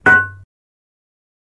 Звуки лома
Удар по глухому объекту